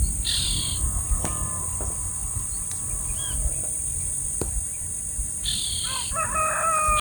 Angú (Donacobius atricapilla)
Nombre en inglés: Black-capped Donacobius
Localidad o área protegida: Concepción del Yaguareté Corá
Condición: Silvestre
Certeza: Fotografiada, Vocalización Grabada